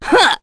Epis-Vox_Attack1.wav